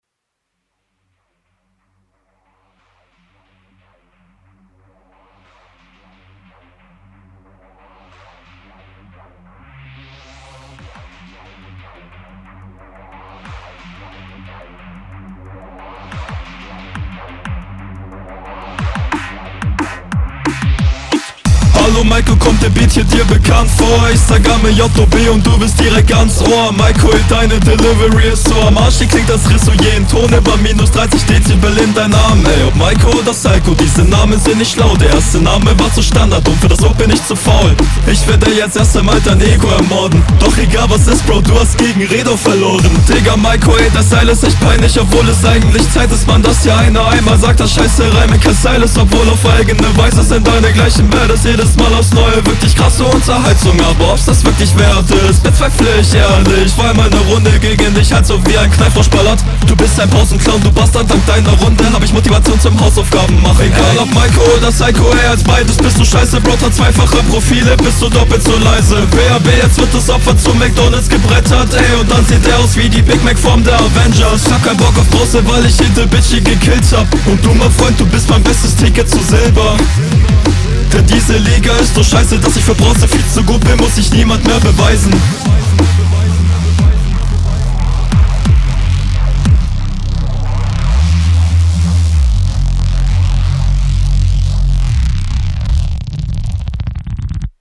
Beat steht dir halt absolut, da kann der enemy nicht viel machen.